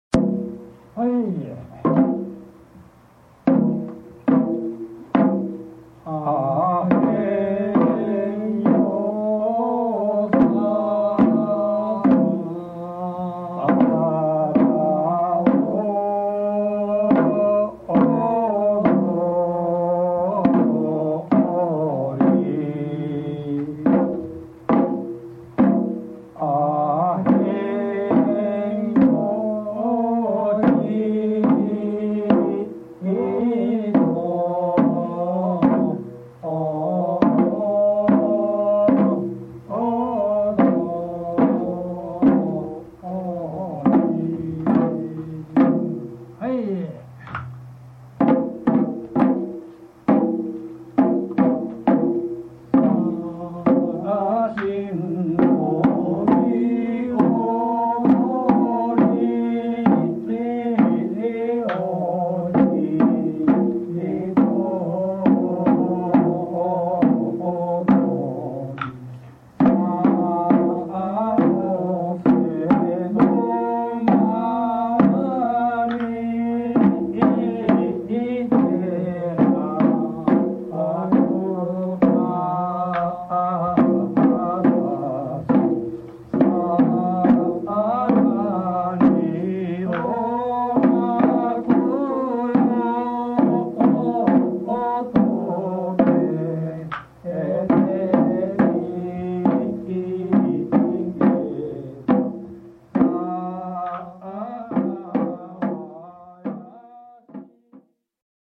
本宮町大瀬〈7〉真昼の盆踊り
三人の方が首から太鼓を下げて、真ん中で踊りながら叩き始める。
最初の曲はゆったりしたテンポの「お庭参り」（入場）
（ぶーんという音は扇風機です）
「はいっ」の合図でテンポが変わって2曲目の「へんよう」（走り）に流れ込む。
曲が変わるたび、微妙にテンポを速めていく太鼓につられて私も思わず腰が浮きそう。
曲間はまったくなくて、テンポを変えながら流れるように続いていく。